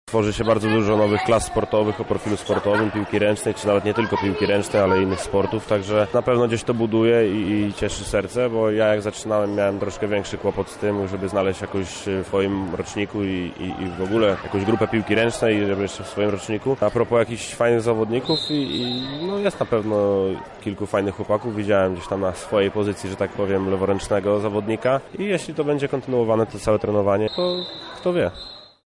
Rozgrywający reprezentacji Polski, rodowity lublinianin Michał Szyba podkreśla rolę odpowiednich warunków szkolenia młodych szczypiornistów w kontekście rozwoju ich karier: